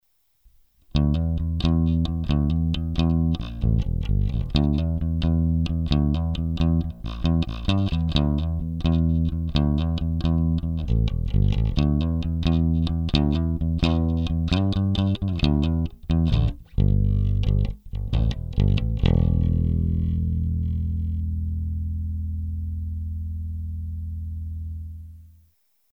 Треки записывал напрямую в линейный вход звуковой карточки без какой либо текущей и последующей обработки звука.
Bass track 1
Треки 1, 2 и 3 записаны при положении всех регулировках (High, Bass) на максимуме; 1-й трек - при включенном "Bright" (т.е. при самом верхнем положении тумблера SW 1); 3-й трек - при отсечке одной катушки хамбакера (т.е. при тумблере SW 1 в среднем положении)
bass_track1.mp3